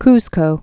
(kzkō, ks-) also Cus·co (ks-)